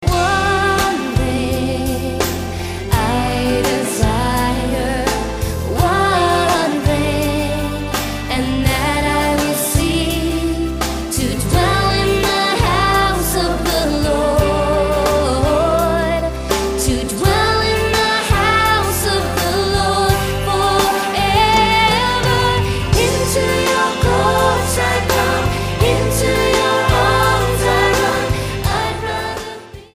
STYLE: Pop
Unlike a lot of contemporary live worship albums